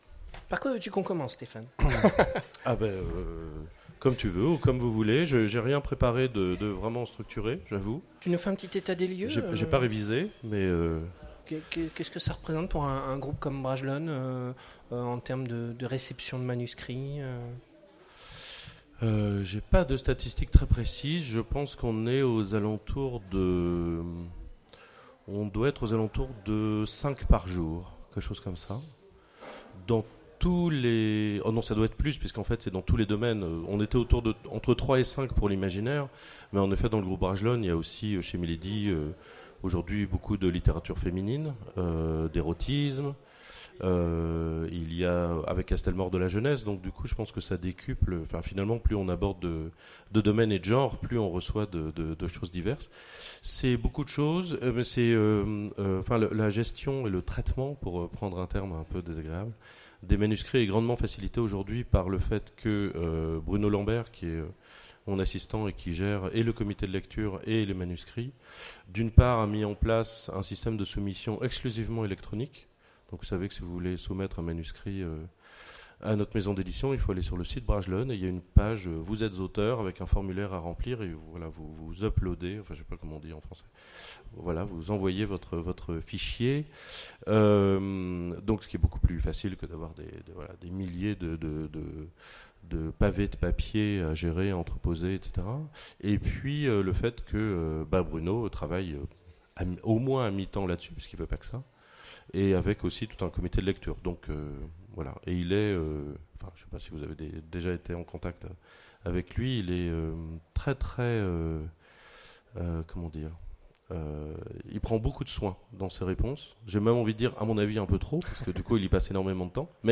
Imaginales 2016 : Conférence Écrire et publier